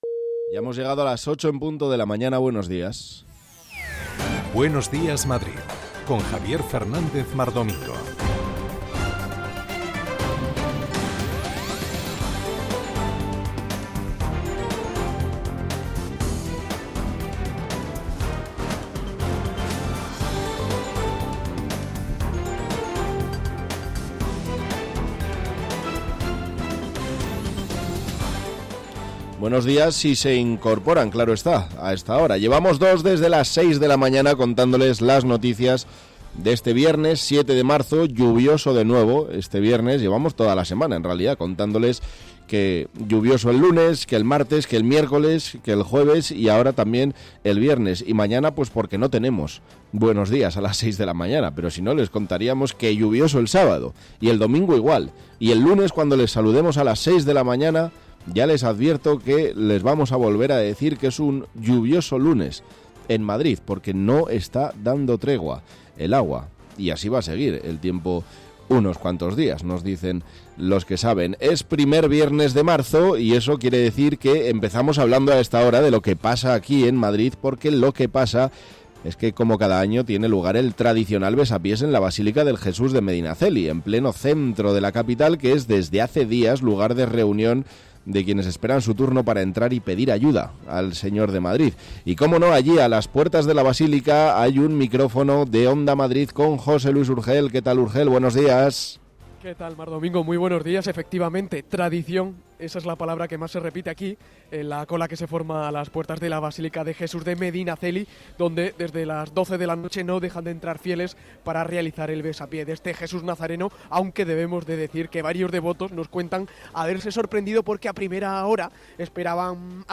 Actualidad, opinión, análisis, información de servicio público, conexiones en directo, entrevistas…